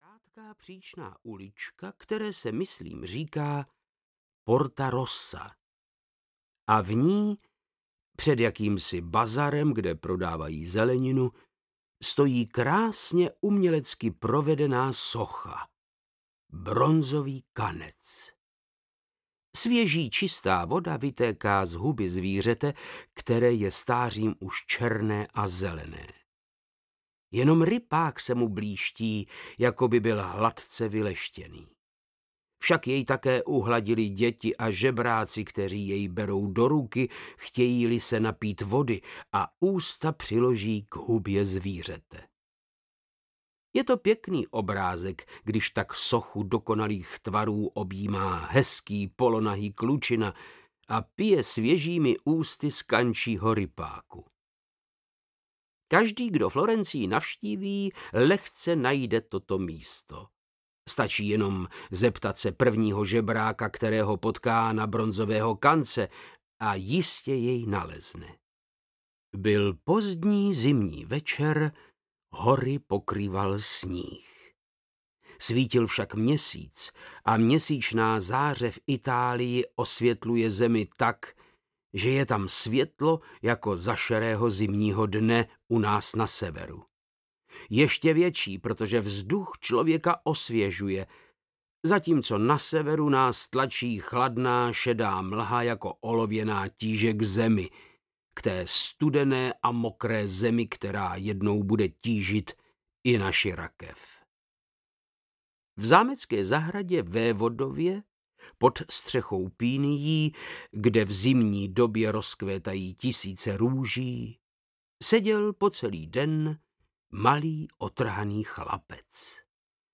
Bronzový kanec audiokniha
Ukázka z knihy
• InterpretVáclav Knop